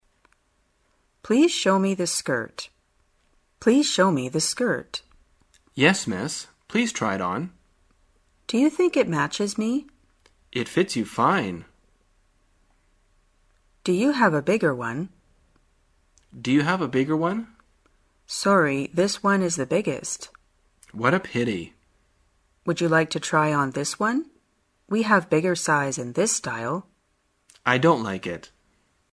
在线英语听力室生活口语天天说 第150期:怎样试穿衣服的听力文件下载,《生活口语天天说》栏目将日常生活中最常用到的口语句型进行收集和重点讲解。真人发音配字幕帮助英语爱好者们练习听力并进行口语跟读。